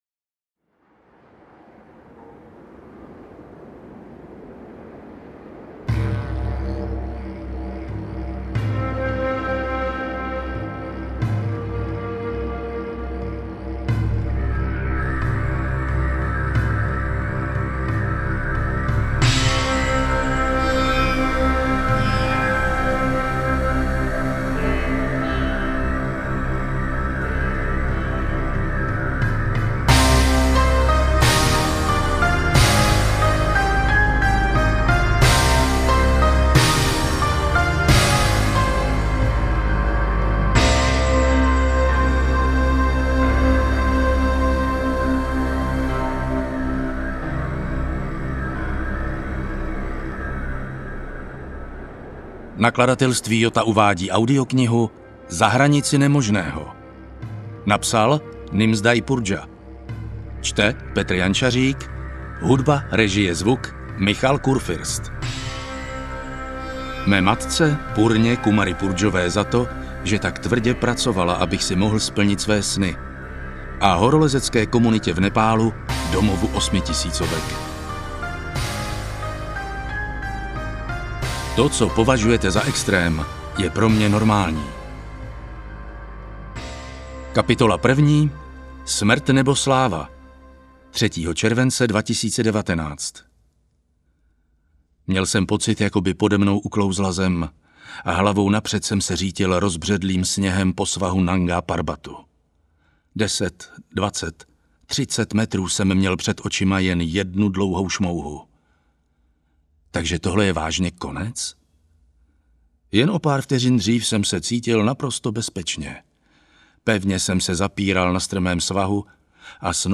AudioKniha ke stažení, 23 x mp3, délka 10 hod. 25 min., velikost 570,9 MB, česky